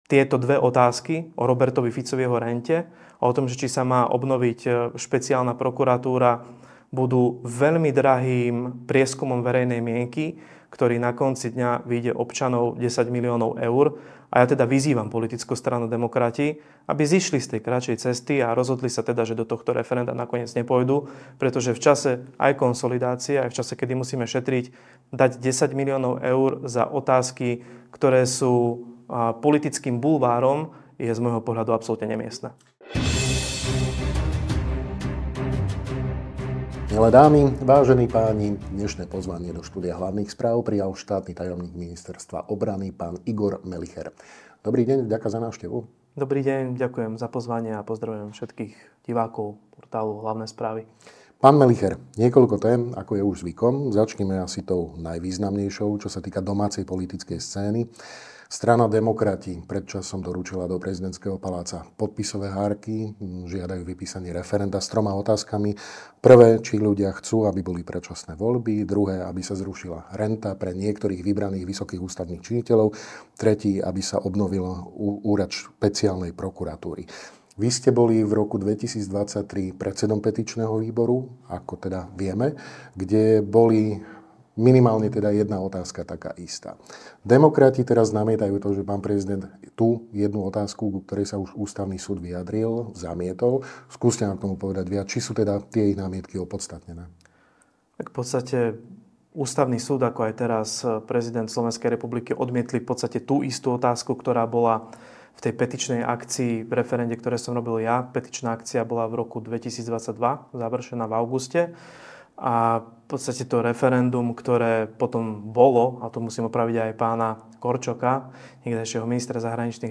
Viac sa dozviete vo videorozhovore so štátnym tajomníkom Ministerstva obrany SR, Mgr. Igorom Melicherom.